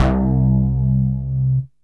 SYNTH BASS-1 0010.wav